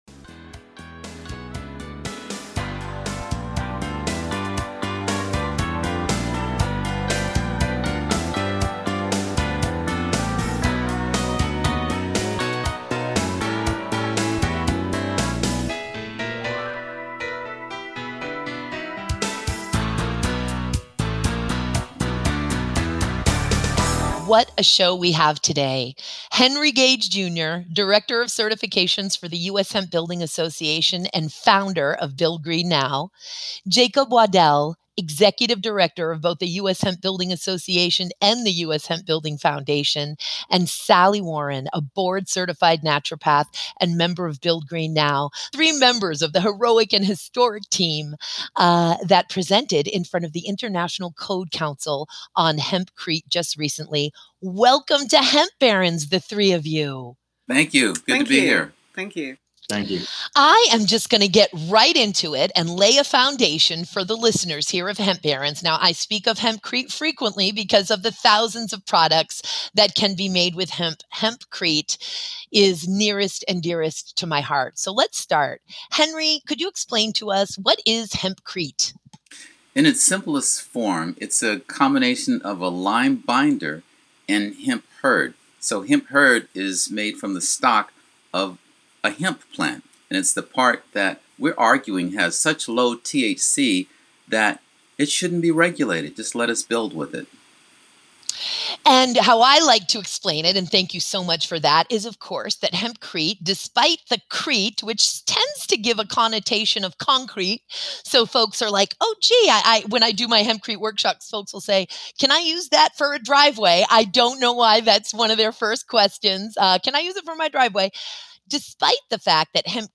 Each week she speaks with the hemp pioneers whose companies, products & advocacy are helping this amazing plant change the world.